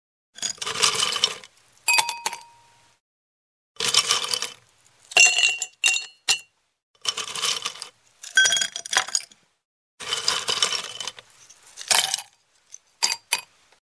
Кубики льда набирают и кидают в стакан